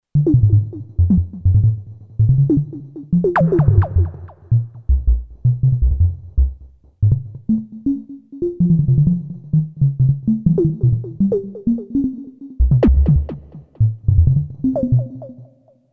NPC Speech Sounds
Each NPC will have their own unique sounding voice pre-generated using synthesizers.
NOTE: These examples use heavy delay/echo which will not feature on the actual speech sounds.